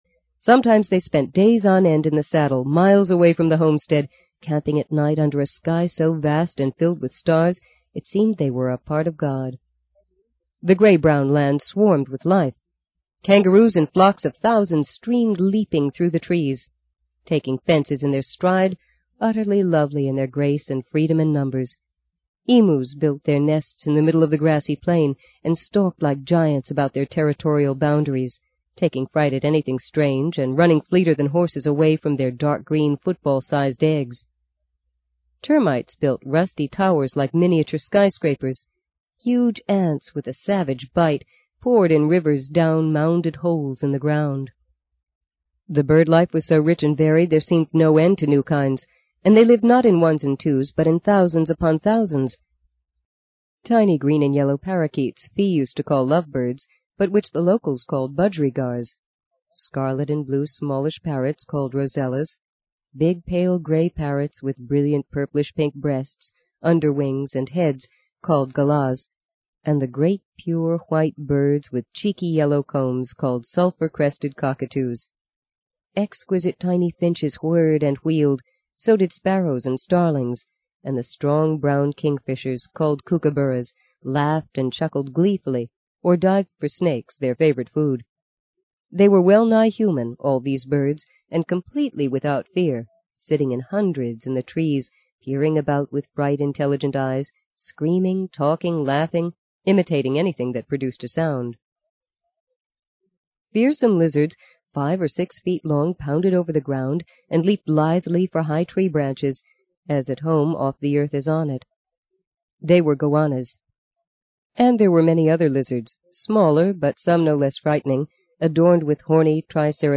在线英语听力室【荆棘鸟】第四章 04的听力文件下载,荆棘鸟—双语有声读物—听力教程—英语听力—在线英语听力室